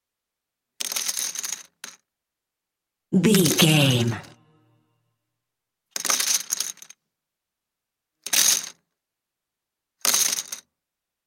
Casino 20 chips table x5
Sound Effects
foley